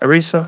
synthetic-wakewords
ovos-tts-plugin-deepponies_Joe Rogan_en.wav